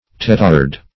Search Result for " tetard" : The Collaborative International Dictionary of English v.0.48: Tetard \Te*tard"\, n. (Zool.)